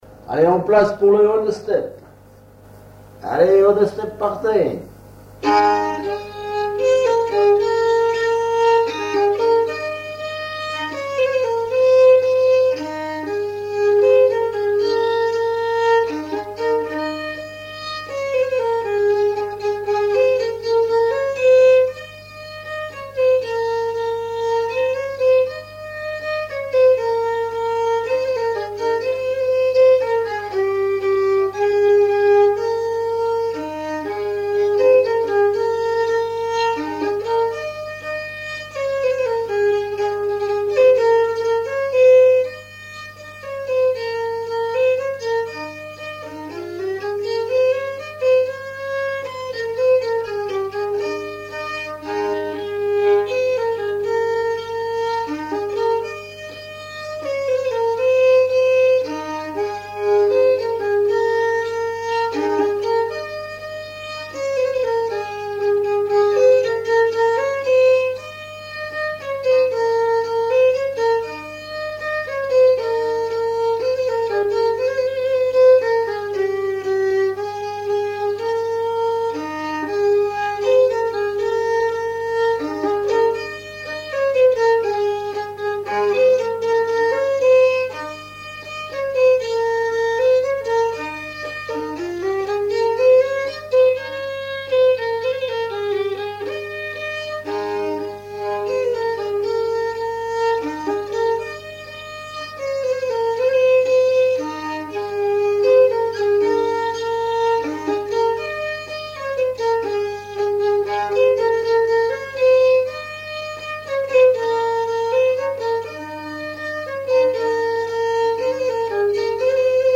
danse : one-step
Genre brève
Auto-enregistrement
Pièce musicale inédite